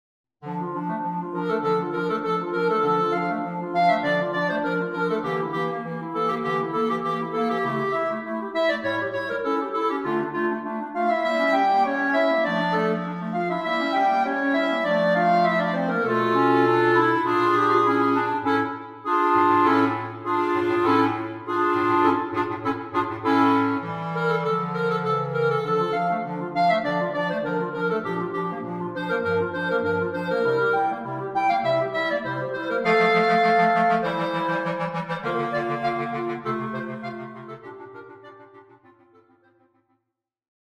simplified and concise arrangement
Bass Clarinet
3 Clarinets and Bass Clarinet